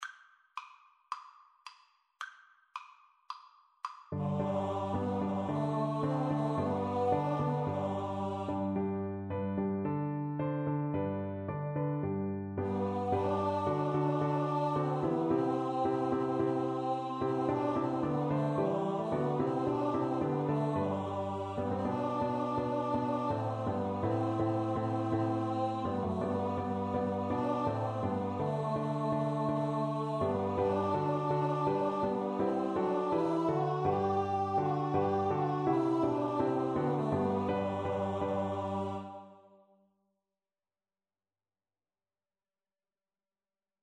Free Sheet music for Choir (SATB)
Allegro moderato = c. 110 (View more music marked Allegro)
4/4 (View more 4/4 Music)
Classical (View more Classical Choir Music)